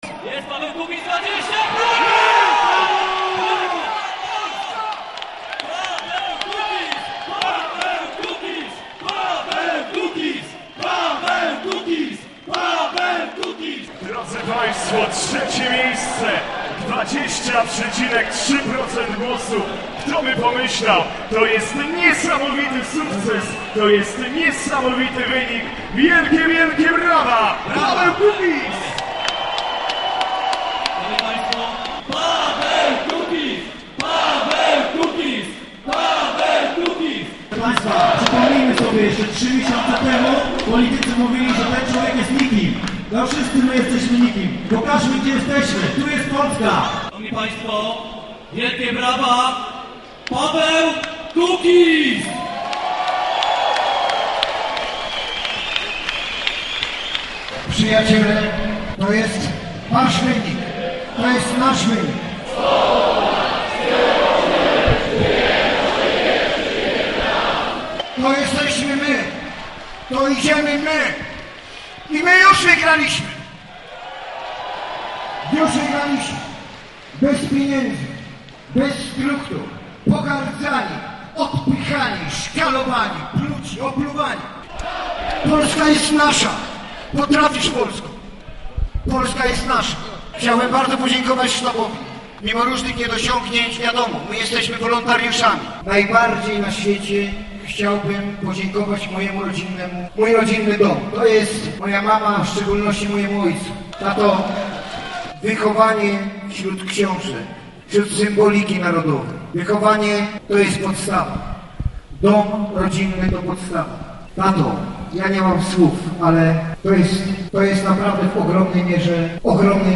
To był szalony, gorący i niezwykle emocjonujący wieczór wyborczy Pawła Kukiza, który zorganizowano w hali Regionalnego Centrum Sportowego w Lubinie.
Ludzie skandowali, śpiewali, bili brawo, były kwiaty i transparenty, niektórzy nawet płakali.
Wieczór wyborczy Pawła Kukiza w Lubinie.